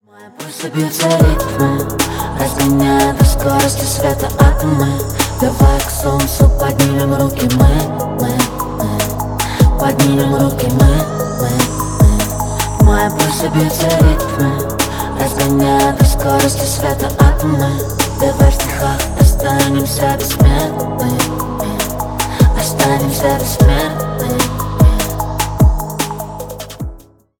Поп Музыка
спокойные # тихие